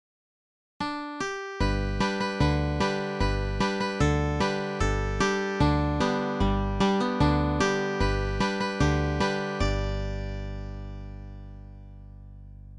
(traditional Western - dating to New York